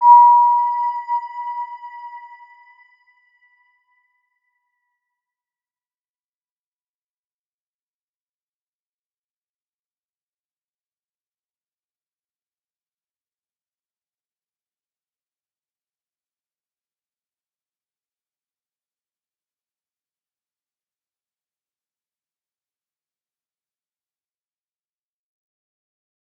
Round-Bell-B5-p.wav